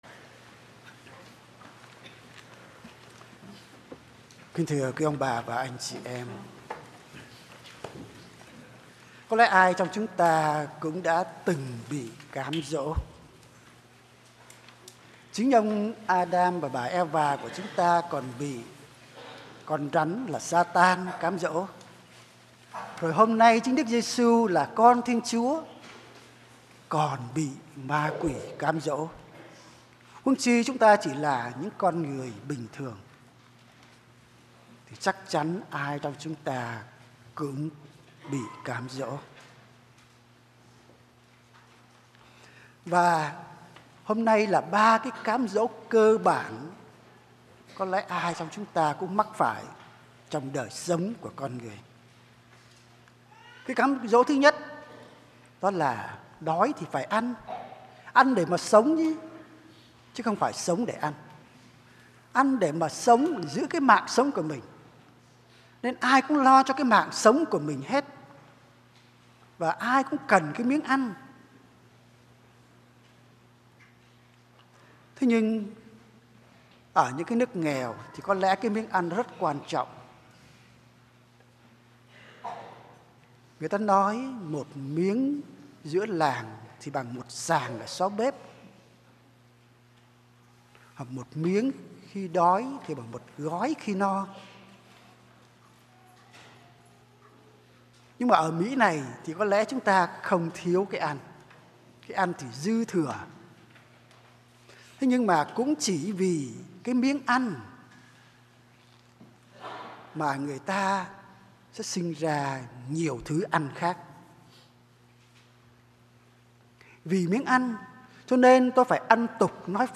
Dòng nhạc : Nghe giảng